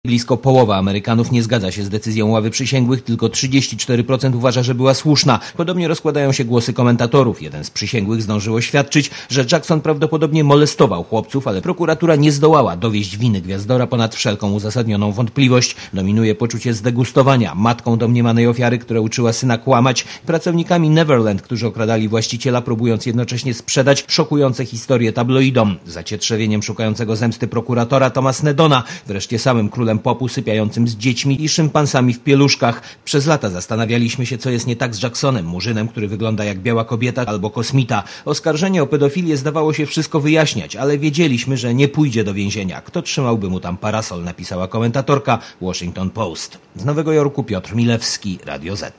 Relacja